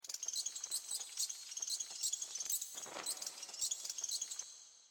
Bats_in_Cave.ogg